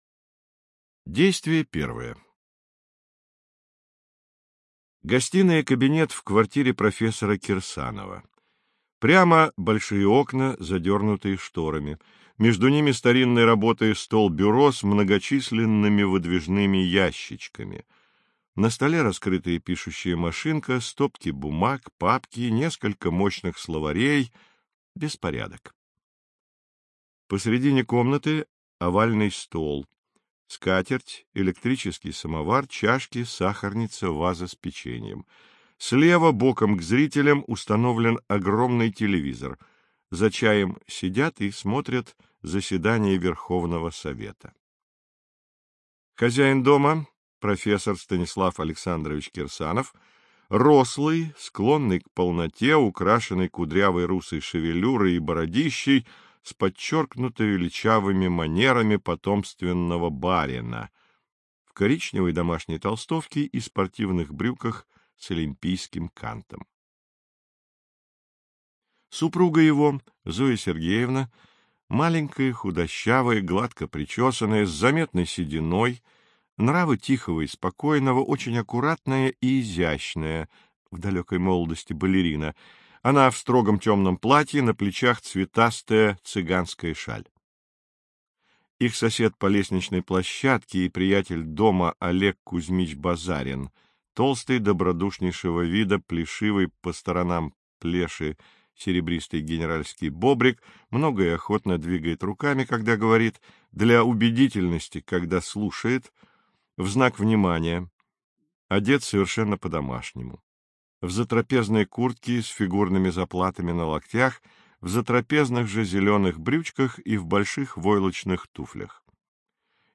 Аудиокнига Жиды города Питера, или Невесёлые беседы при свечах | Библиотека аудиокниг